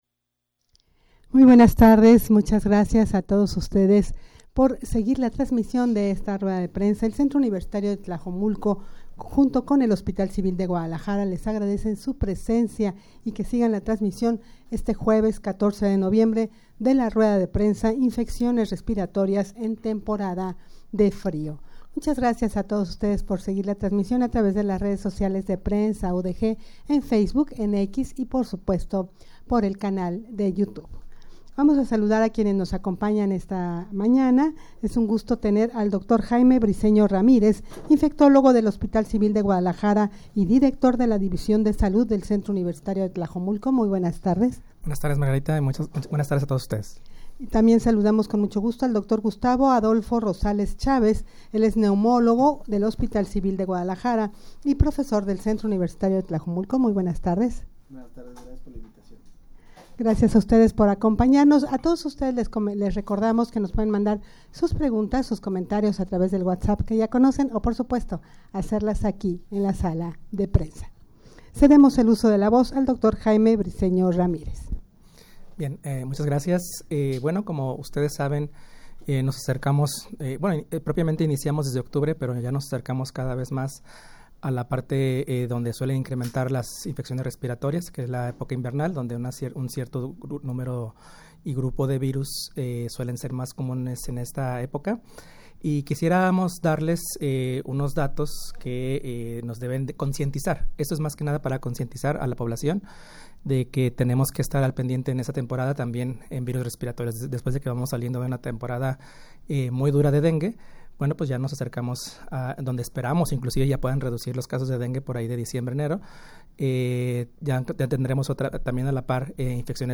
Audio de la Rueda de Prensa
rueda-de-prensa-infecciones-respiratorias-en-temporada-de-frio.mp3